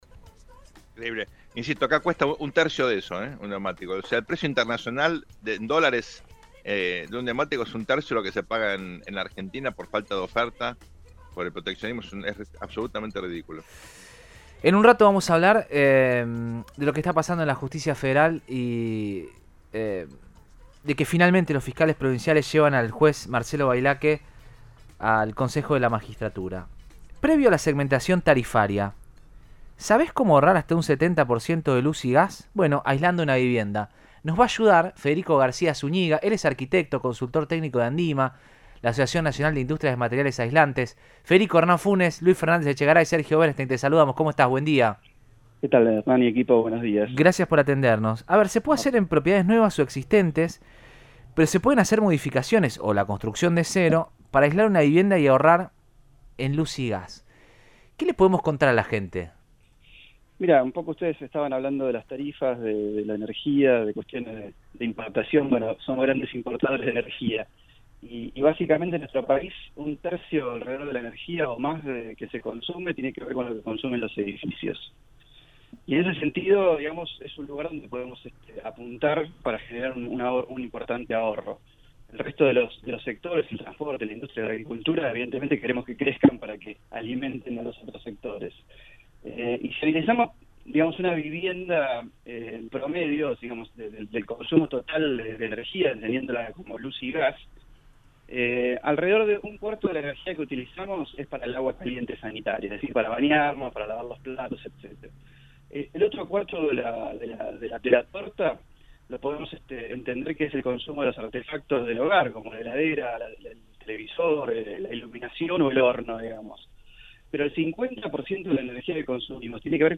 en una charla con Radioinforme 3 de Cadena 3 Rosario